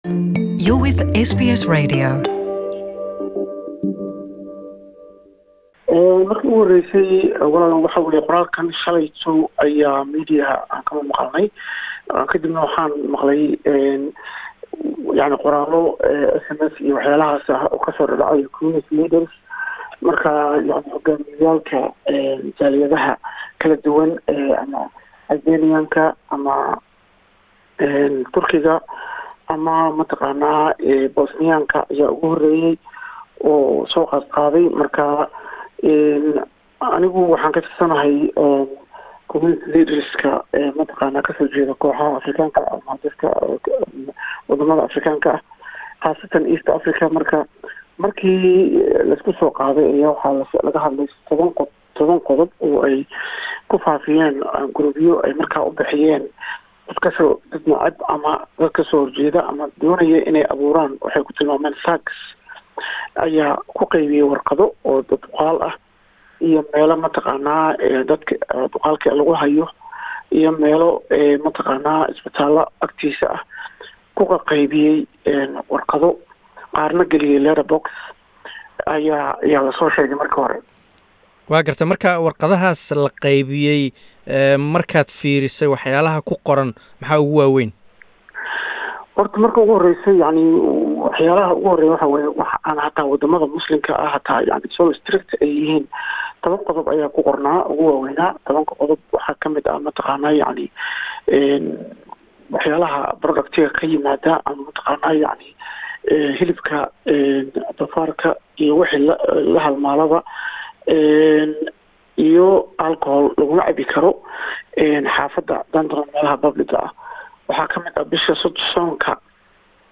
Interview
Waraysi